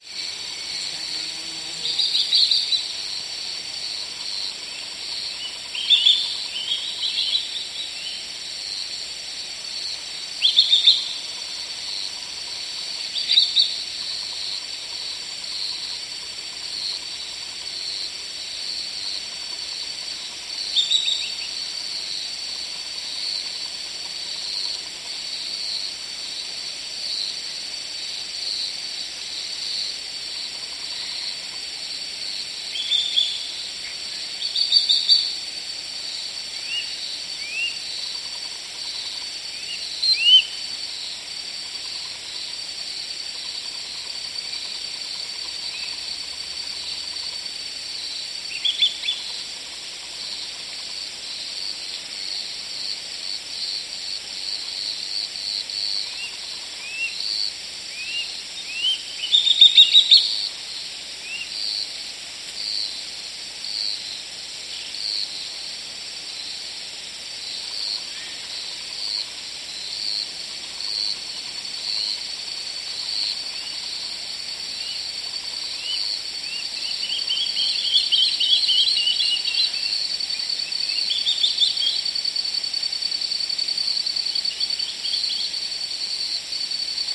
Атмосферные звуки тропического леса в полуденный зной